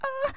Ahha
male meme sweetness vocalization sound effect free sound royalty free Memes